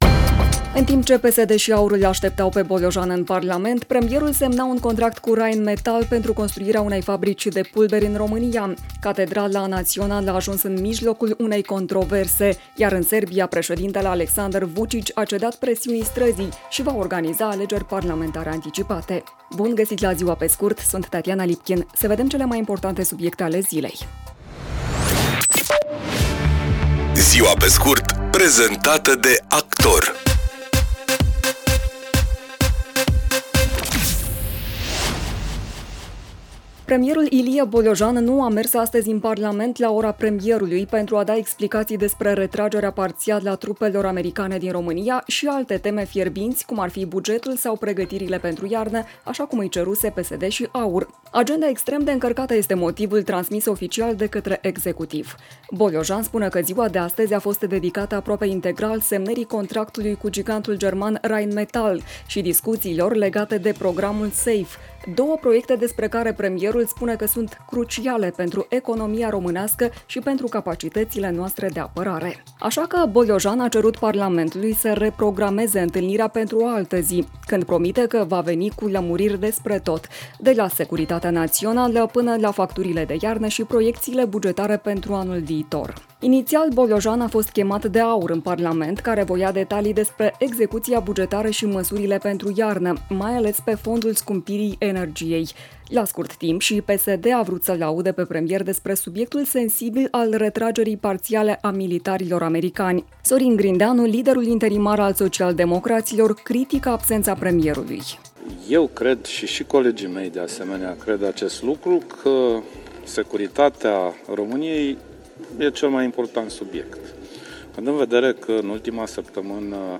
„Ziua pe scurt” este un podcast zilnic de actualitate care oferă, în doar zece minute, o sinteză a principalelor cinci știri ale zilei. Formatul propune o abordare prietenoasă, echilibrată și relaxată a informației, adaptată publicului modern, aflat mereu în mișcare, dar care își dorește să rămână conectat la cele mai importante evenimente.